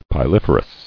[pi·lif·er·ous]